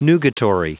Prononciation du mot nugatory en anglais (fichier audio)
Prononciation du mot : nugatory